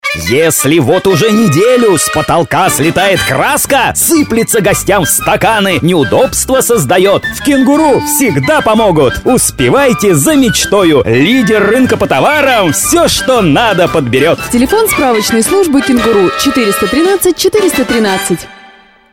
Аудиоролик в духе "Вредных советов".